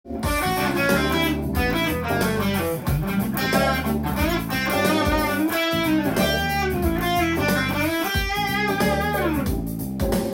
Am　ワンコードのカラオケに合わせて弾けるようにフレーズを入れてみました。